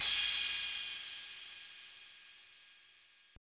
Perc (5).wav